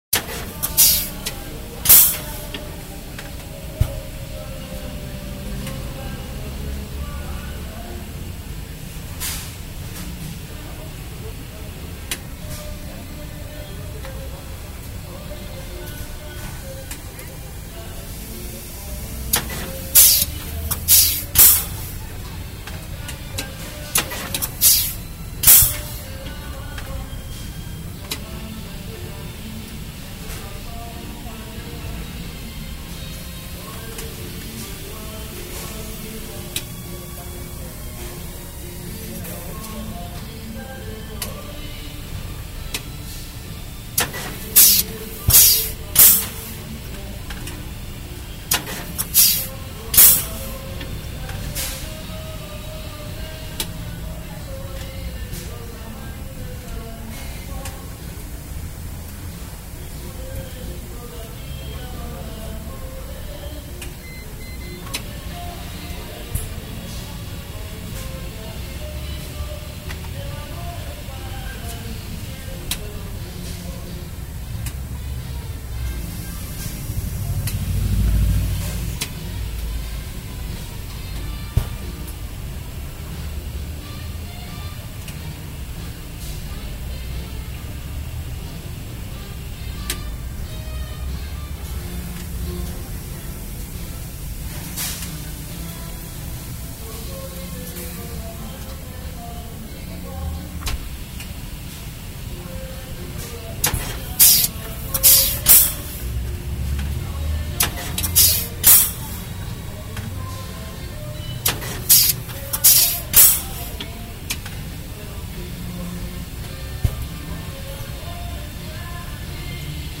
Phonography
The field recordings for this release were taken in various locations around Baja California and Mexico City using binaural microphones. They have been left unprocessed and although lack certain apparentness were not chosen arbitrarily. Varying from very concrete details to everyday situations, what they have in common is that most of the time some kind of ‘live music’ is present – either in involving musicians and instruments (linea 1, street trumpet) or just the inevitable radio playing in the background (red glass, a hole in the day).